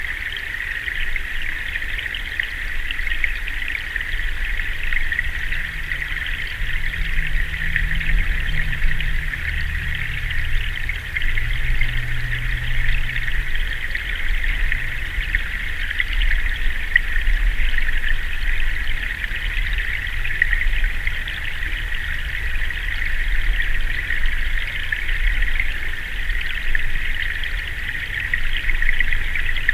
In this next clip, the sound of flowing water is prominent.
The steady rush of water over rocks.
example-constant-flowing-water.mp3